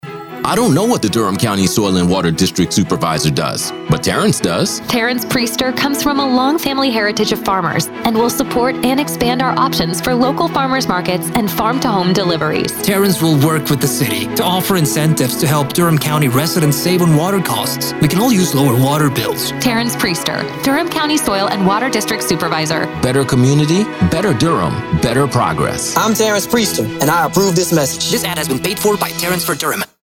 African American, Diverse Political Commercial Voice Over
Profound. Resonant. Real | Voiceovers